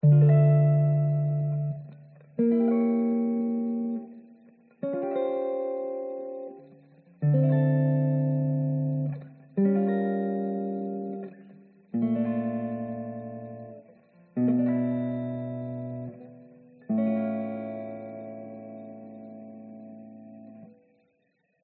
弹吉他和弦
描述：弹吉他和弦。漫不经心的弹奏，使用放大器与吉他软件。
标签： 和弦 吉他 漫不经心的弹奏
声道立体声